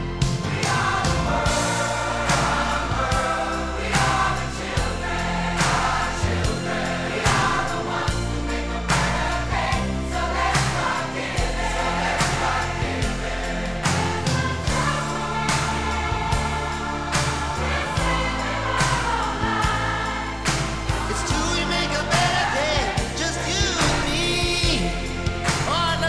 Gattung: Moderner Einzeltitel
Besetzung: Blasorchester
Tonart: Es-Dur auf F-Dur